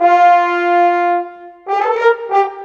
Rock-Pop 07 Horns 03.wav